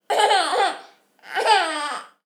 Free baby laughing sound effects in wav and mp3 formats
Baby Laughing 01 Baby laughing sound Keywords: laugh, laughter, giggle, giggling, noise, audio, clip, sound bite, tone
48 kHz 24 bit Stereo
baby-laughing-01.wav